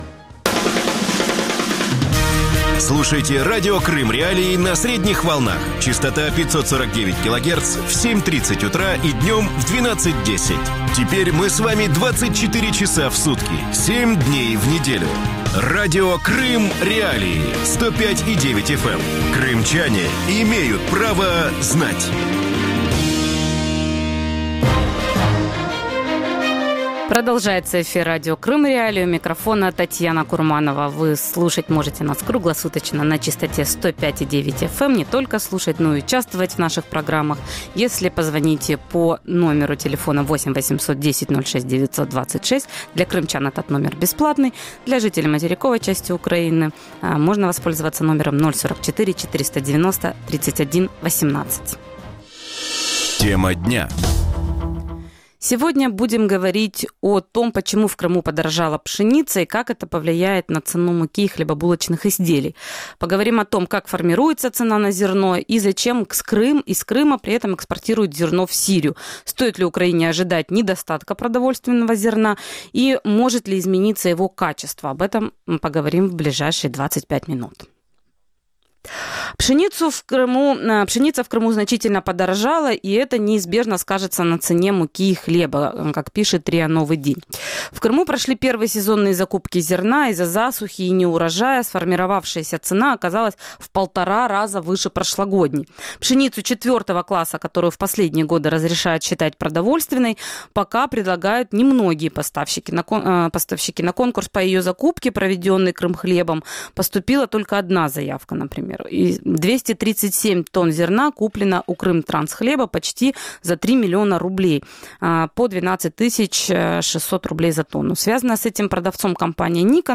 Гости эфира